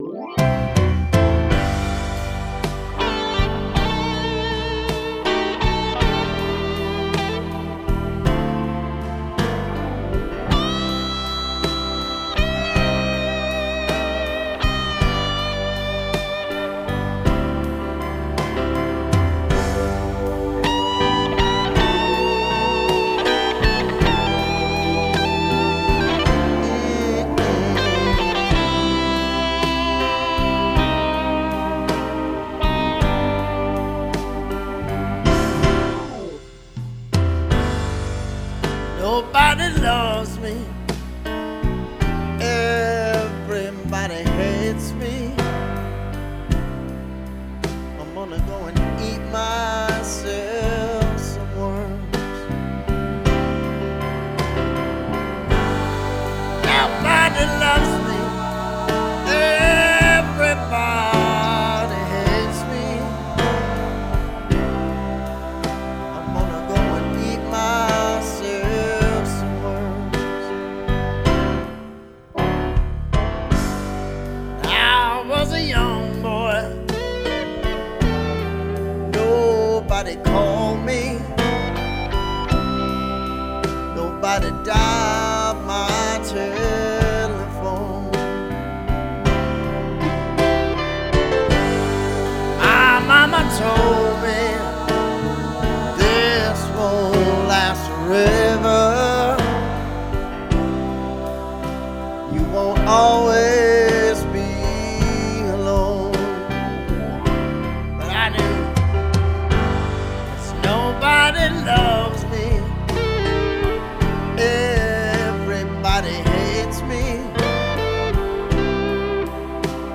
Тип альбома: Студийный
Жанр: Blues-Rock, Soft-Rock